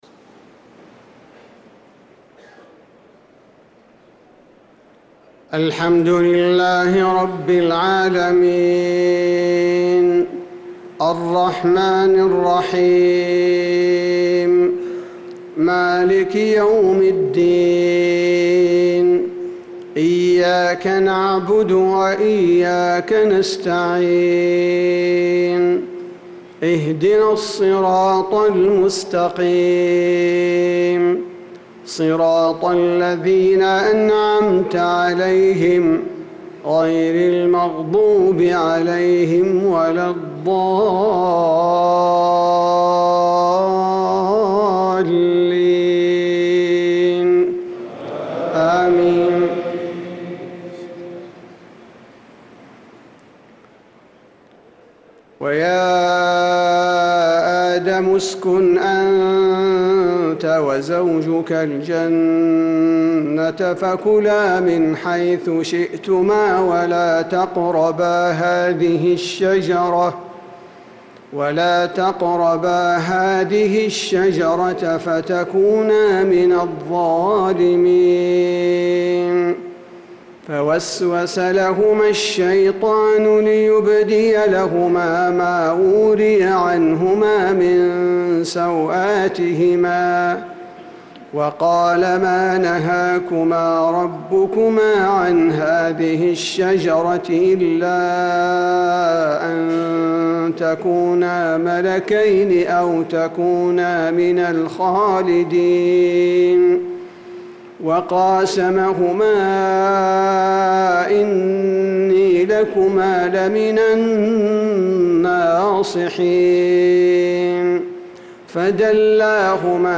صلاة العشاء للقارئ عبدالباري الثبيتي 7 محرم 1446 هـ
تِلَاوَات الْحَرَمَيْن .